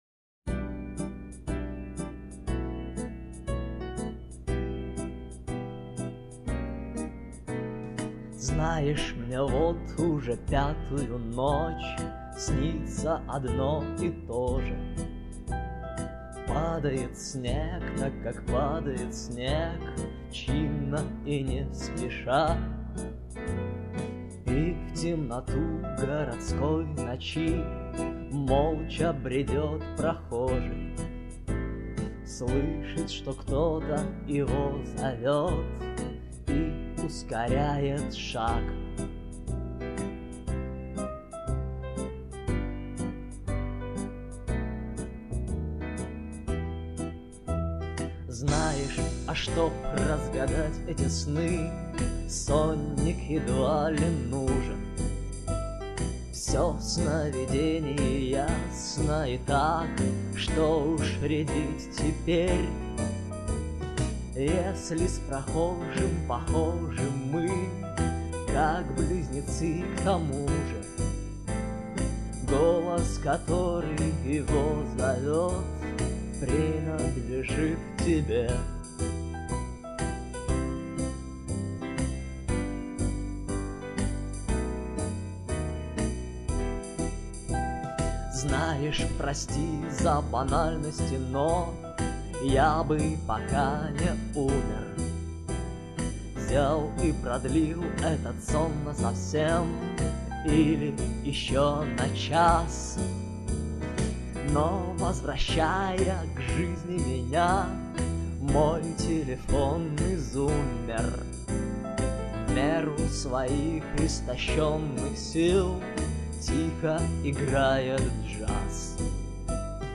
• Жанр: Джаз